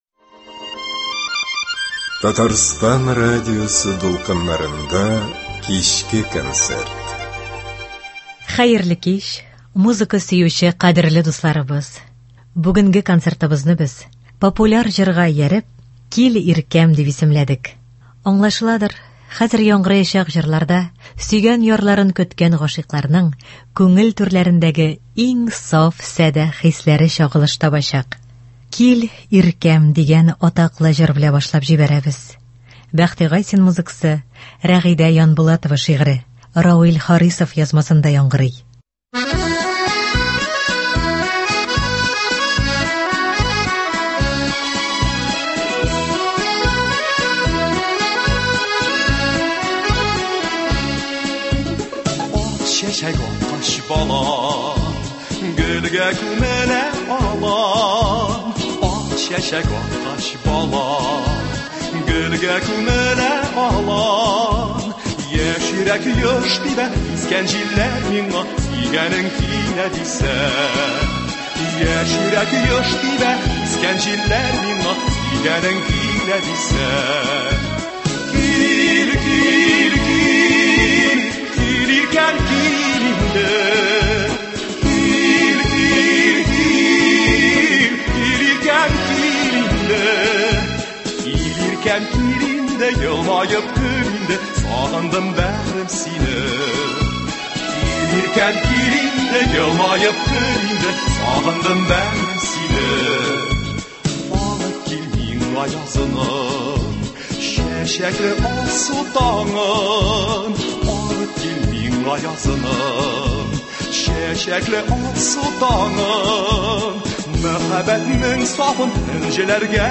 Эстрада концерты.